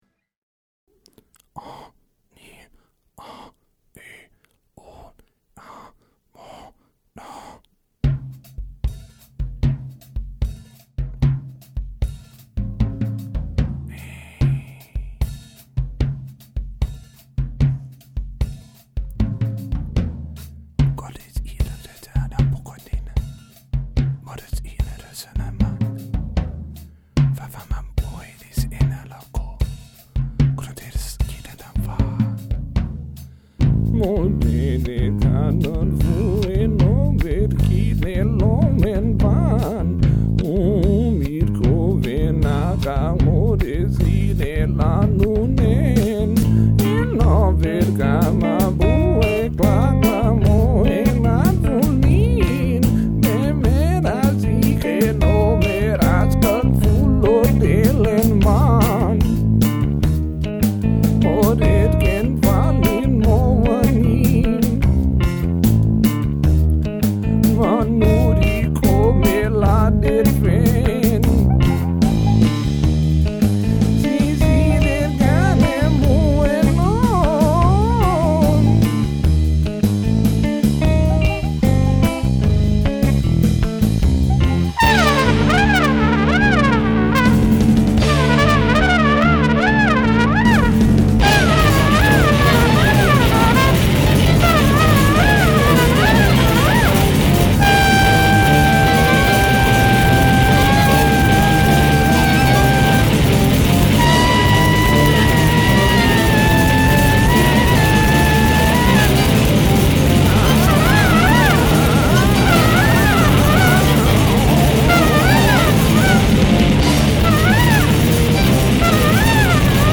Su música la definen como omnivorous-jazz-dada experiment.
100% post-rock-jazz